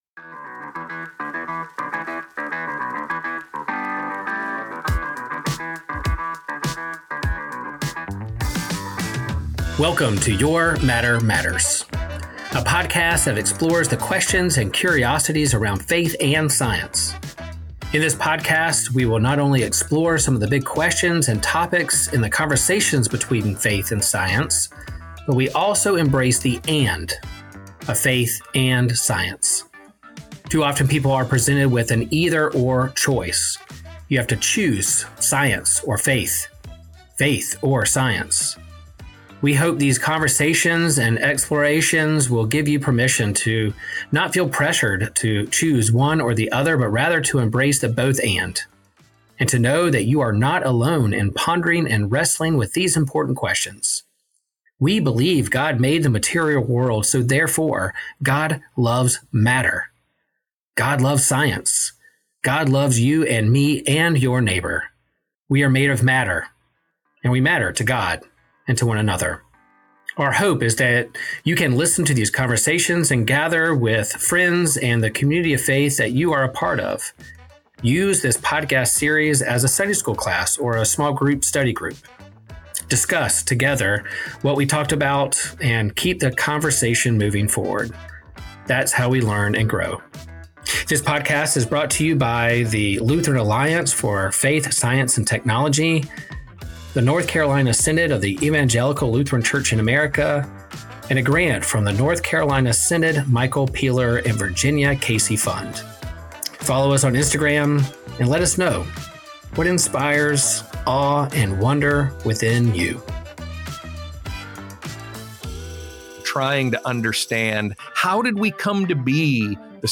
In this episode, the duo engages in a profound reflection on the enlightening conversations they have had throughout the season, addressing the interplay between faith and science.